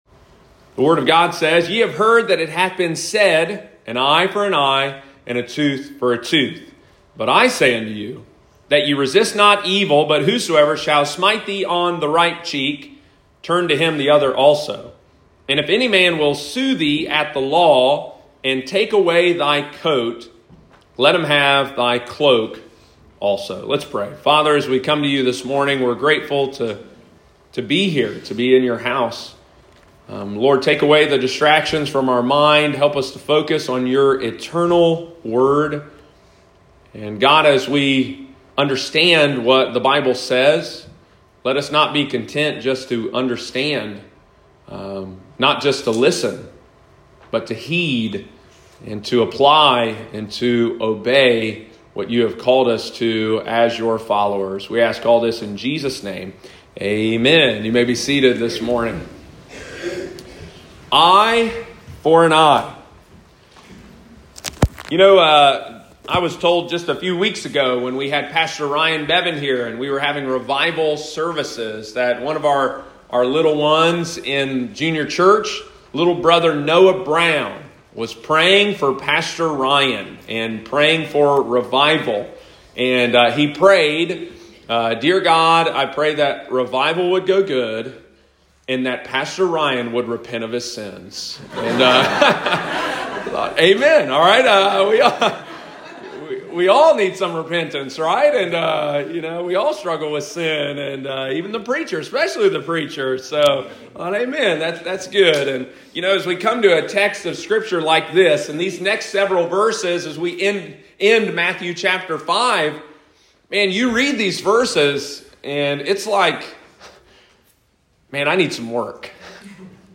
Eye For An Eye – Lighthouse Baptist Church, Circleville Ohio